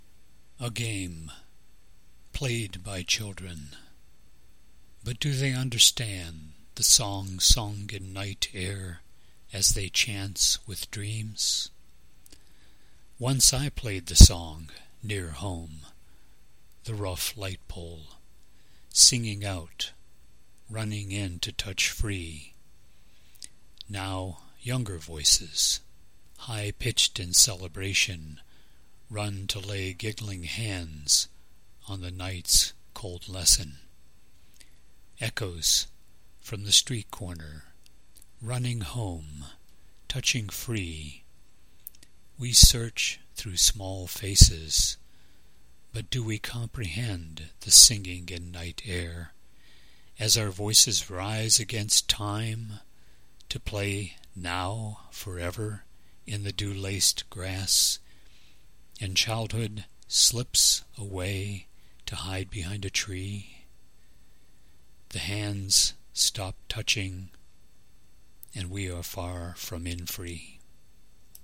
In “Biography of a Poem” I offer readings of some of my published poems with a discussion on the genesis of each work.
Poem “A Game” – Reading